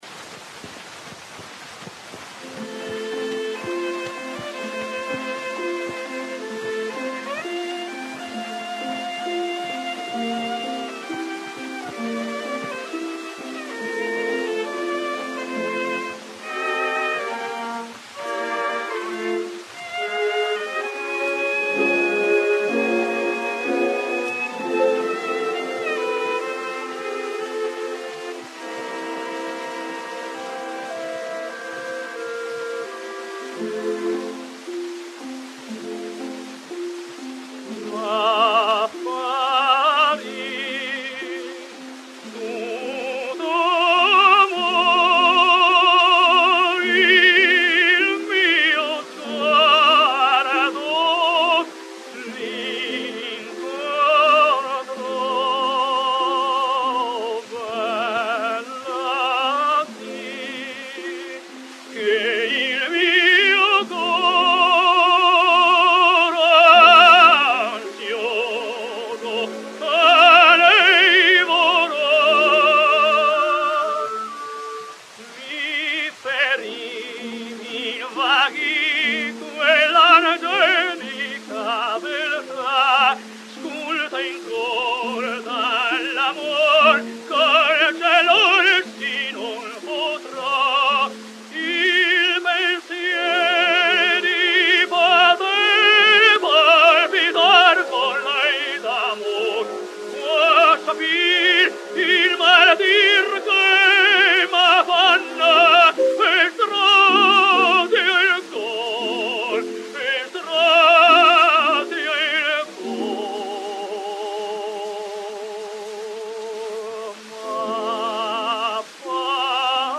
Bonci's high notes extended to a brilliant and ringing C-sharp, and possibly even D. His cadenzas and his ability to diminish a single high long note added to his fame.
Alessandro Bonci sings Martha: